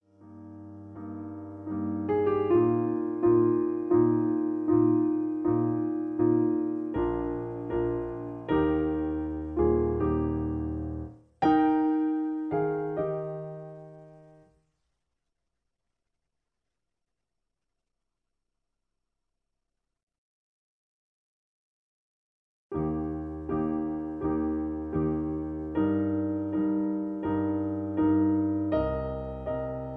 In E flat. Piano Accompaniment